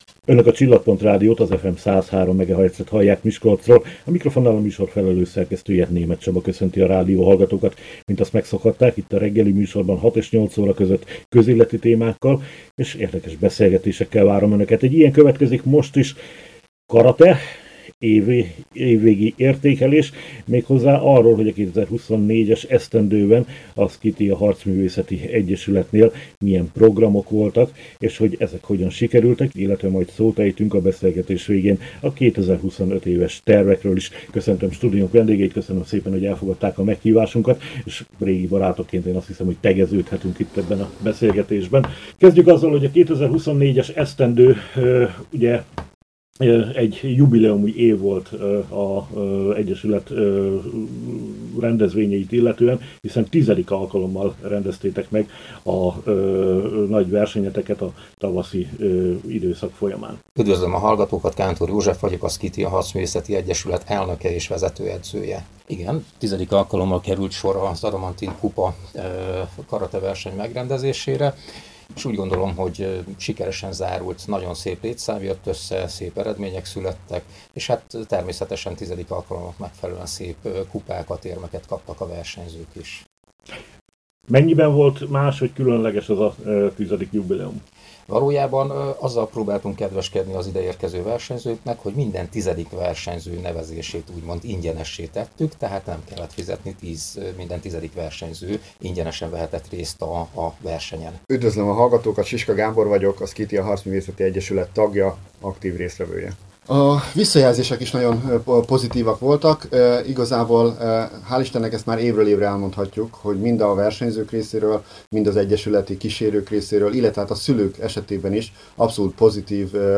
beszélgettünk a Csillagpont Rádió studiójában. Természetesen a 2025-ös tervekről is szót ejtettünk már.